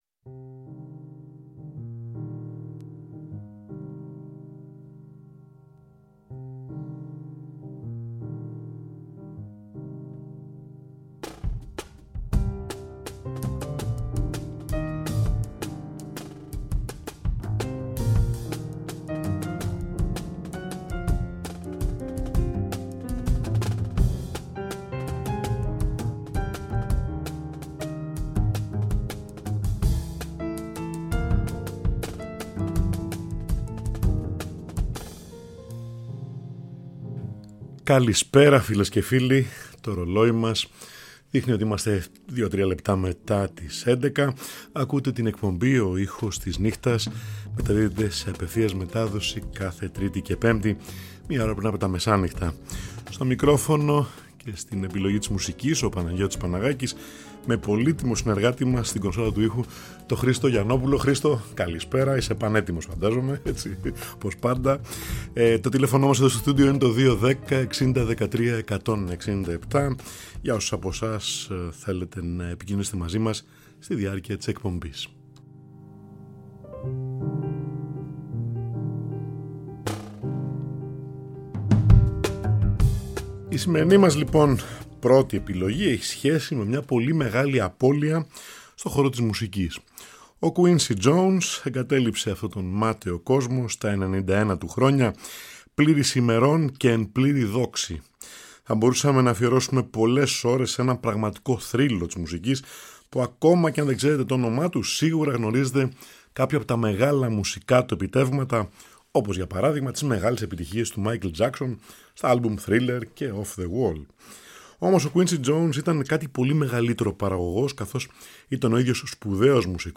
για τσέλο και πιάνο (!), Βραζιλιάνικο (πιανιστικό) άρωμα
2η Σονάτα για Βιολί και Πιάνο